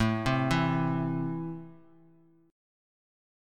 Listen to Am strummed